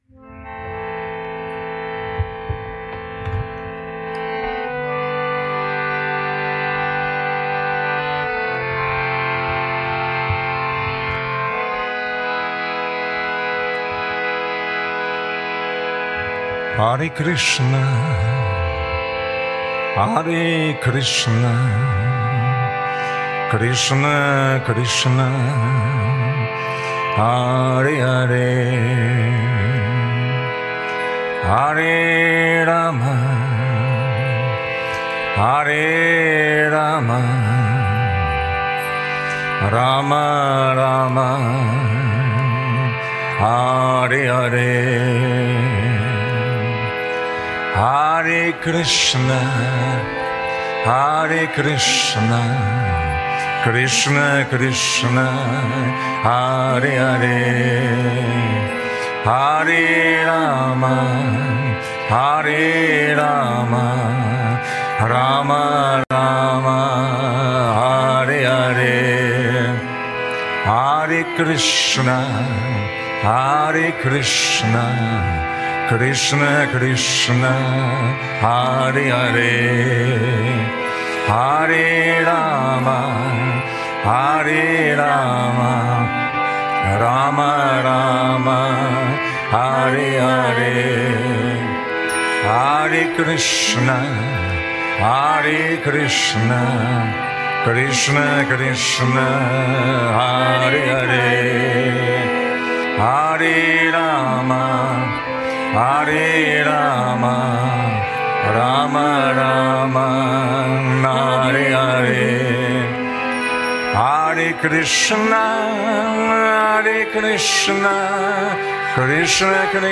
Live Recordings from Yoga Vidya Ashram Germany.
Krishna Kirtan and Mantra Chanting